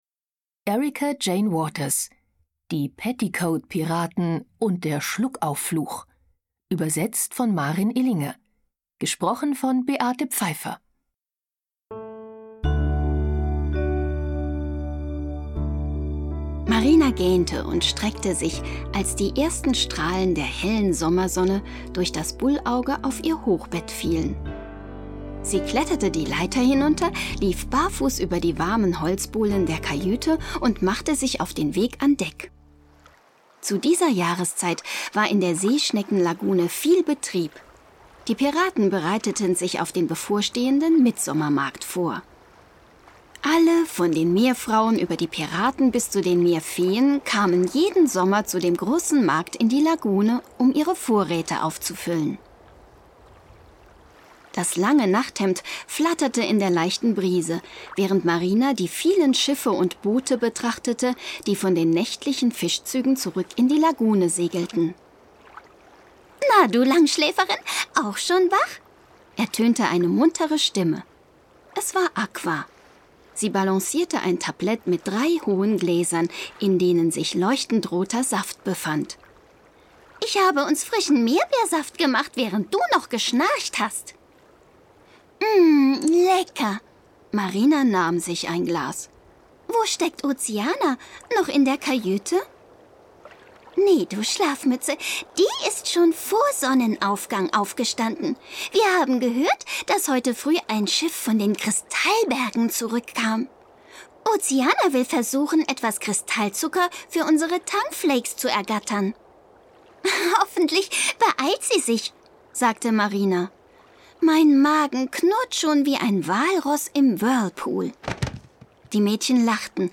Schlagworte Abenteuer • Freundschaft • Hörbuch; Lesung für Kinder/Jugendliche • Mädchen • Piraten • Pirat / Piraterie; Kinder-/Jugendlit. • Pirat / Piraterie / Seeräuber; Kinder-/Jugendlit. • Rätsel • Seeungeheuer